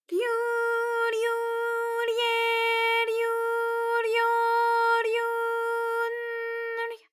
ALYS-DB-001-JPN - First Japanese UTAU vocal library of ALYS.
ryu_ryu_rye_ryu_ryo_ryu_n_ry.wav